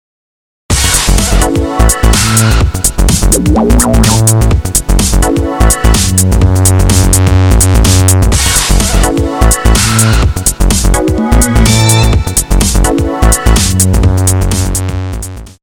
Growling bassline and synthy stabs